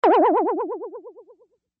boing_comical_accent